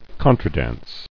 [con·tra·dance]